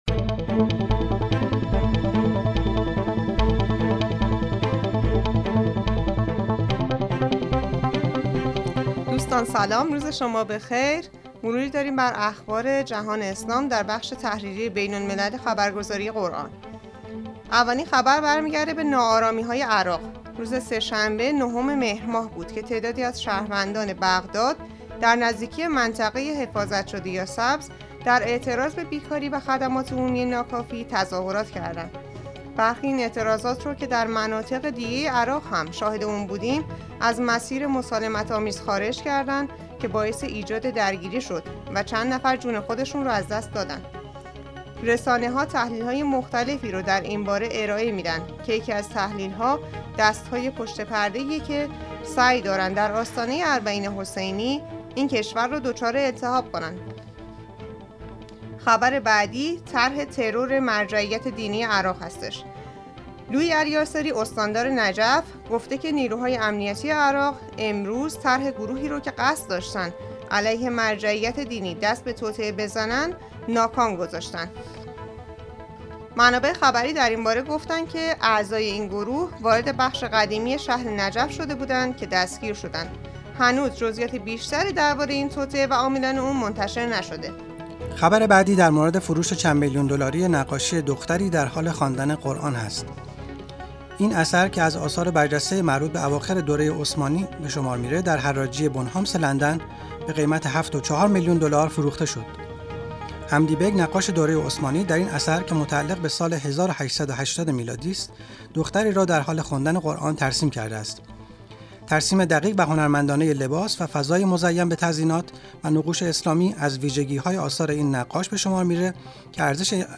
گروه چند‌رسانه‌ای ــ تحریریه بین‌الملل ایکنا در بسته خبری صوتی اخبار جهان اسلام در هفته گذشته را در قالب یک پادکست مرور کرده است.